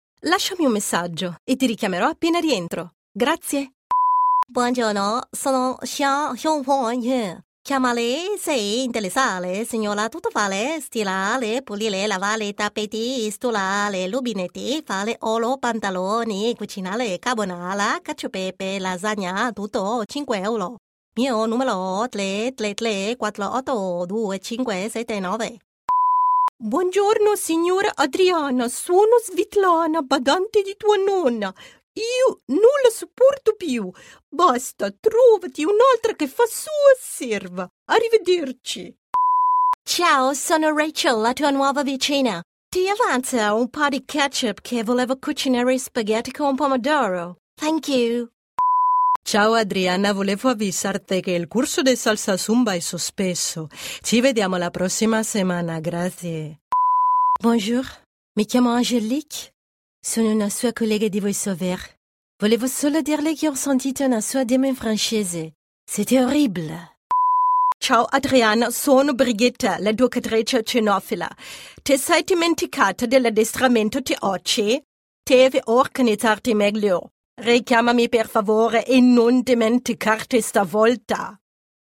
Never any Artificial Voices used, unlike other sites.
Female
Yng Adult (18-29), Adult (30-50)
Very versatile voice particularly suitable for any project that needs to sound natural, friendly, casual and engaging.
You can also hire my voice for projects with italian accent in both english and german.